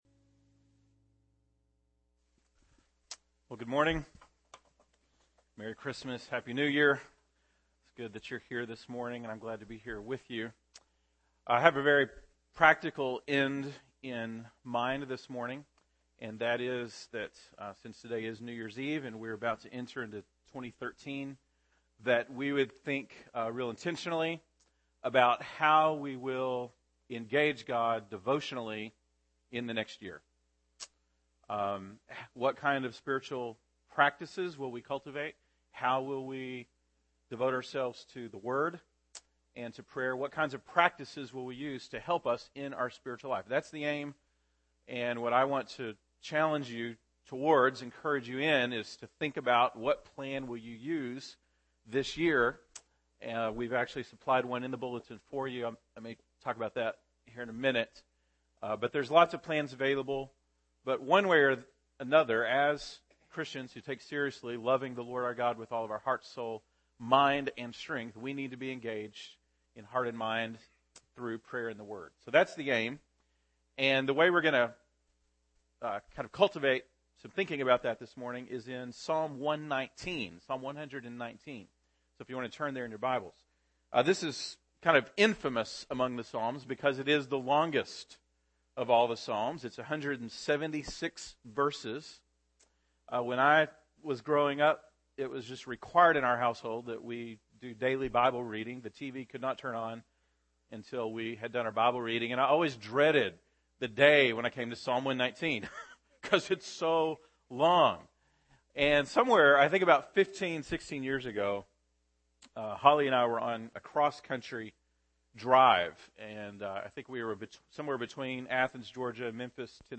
December 30, 2012 (Sunday Morning)